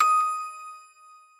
sfx_intermission.mp3